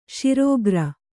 ♪ śirōgra